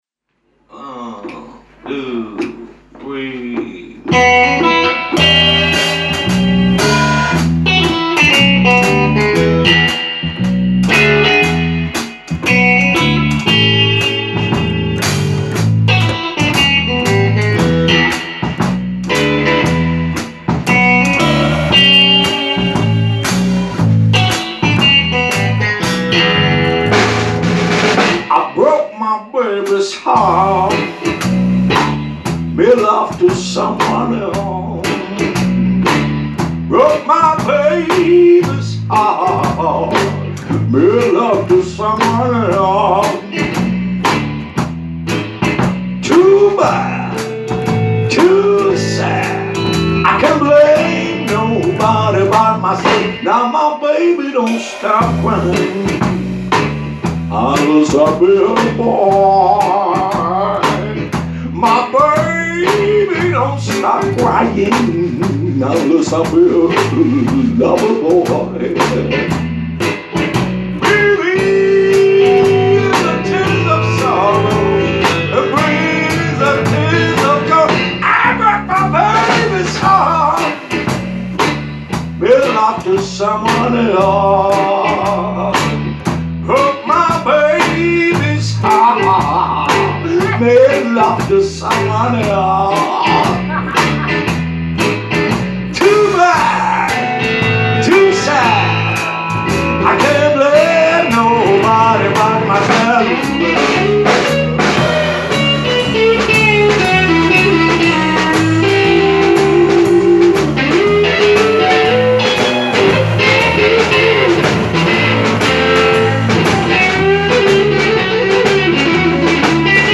Ηχογραφήθηκε με κινητό βέβαια, first take για την αλήτισα και για μας. Προβολή συνημμένου broke my babys heart rehearsal.mp3